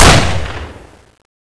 sol_reklam_link sag_reklam_link Warrock Oyun Dosyalar� Ana Sayfa > Sound > Weapons > Aug Dosya Ad� Boyutu Son D�zenleme ..
WR_fire.wav